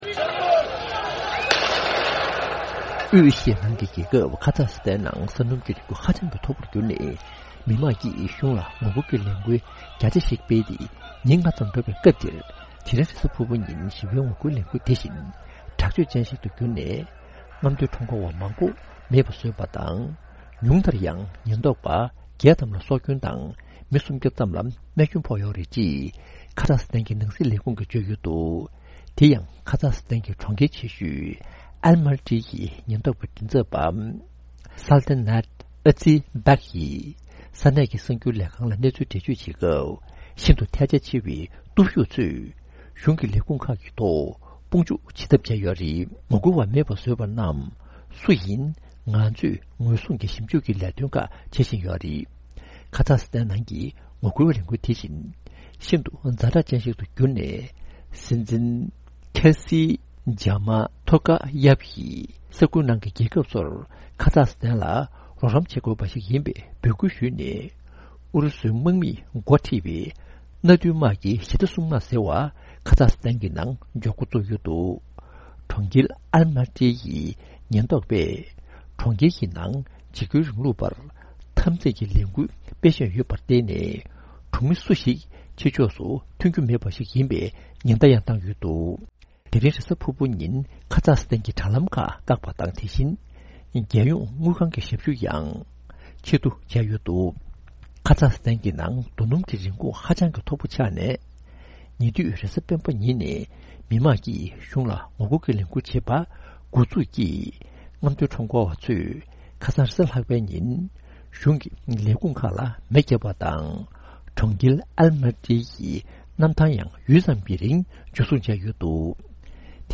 ༄༅།། དབུས་ཨེ་ཤི་ཡའི་རྒྱལ་ཁབ་ཁ་ཛཀ་སི་ཐན་ལ་རྡོ་སྣམ་གྱི་རིན་གོང་ཧ་ཅང་འཕར་ནས་མི་དམངས་ཀྱིས་གཞུང་ལ་ངོ་རྒོལ་ཤུགས་ཆེན་བྱས་ཏེ་སྲིད་གཞུང་གིས་དགོངས་པ་ཞུ་དགོས་བྱུང་ཡོད་པའི་སྐོར། འདི་ག་རླུང་འཕྲིན་ཁང་གི་གསར་འགོད་པས་སྤེལ་བའི་གནས་ཚུལ་ཞིག